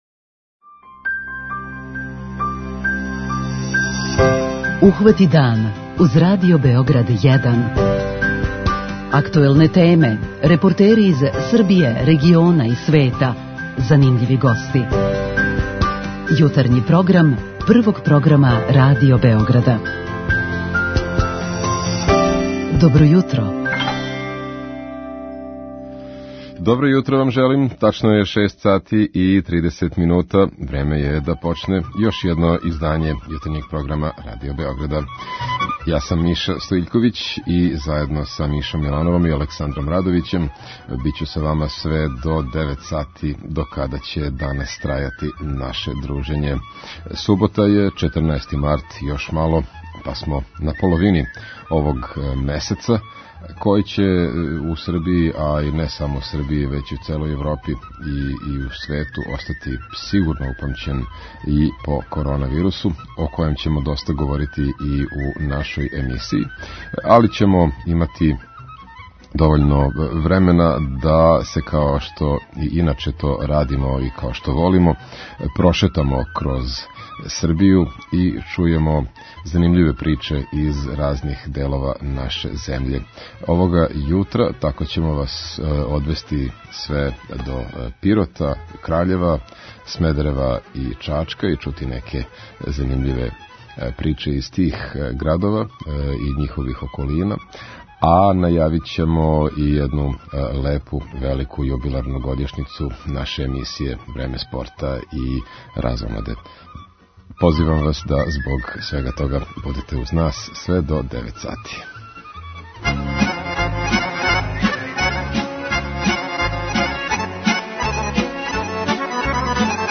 Поред тога, доносимо неколико занимљивих репортажа из Србије: о мобилном информативном центру МУП-а који ове недеље обилази села Пиротског округа; о селу Мланча у Студеничком крају где има све више пословних људи, посетилаца и туриста; смедеревској Гимназији из 1871. која је рекноструисана и поново ради, о бројним програмима који ће бити организовани у чачанској библиотеци поводом годишњице рада.